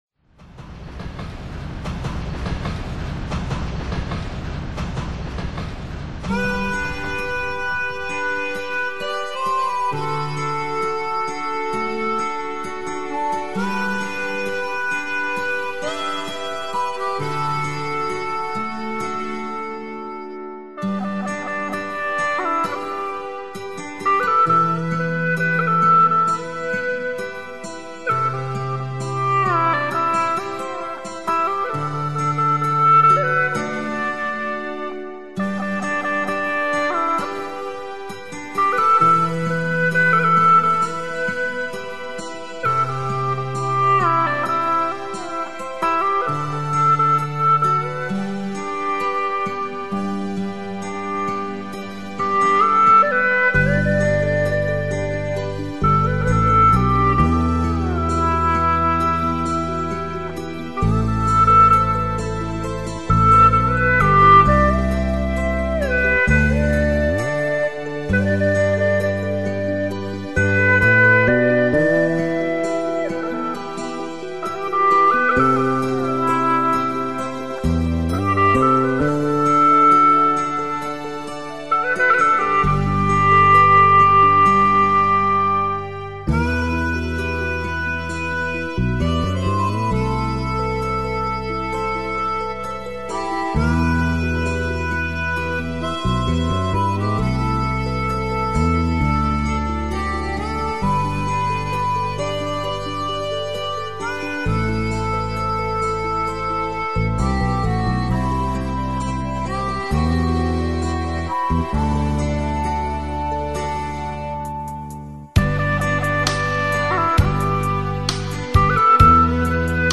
调式 : G 曲类 : 流行